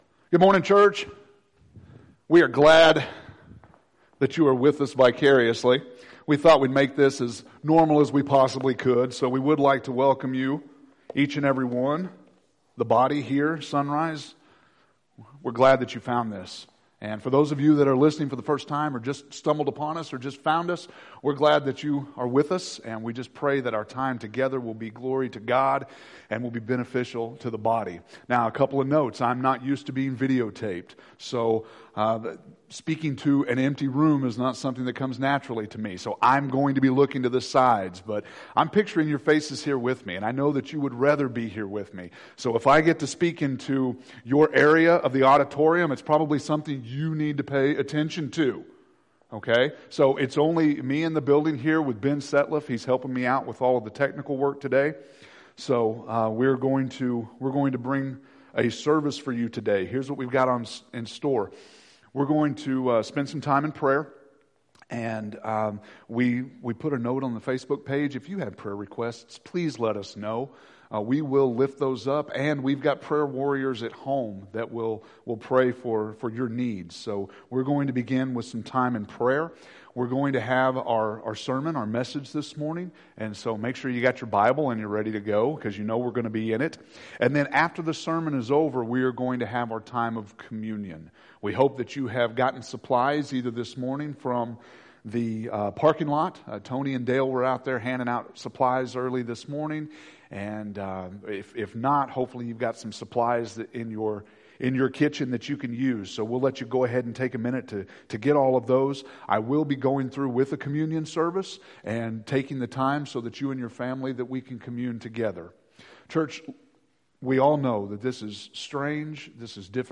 March 29th – Sermons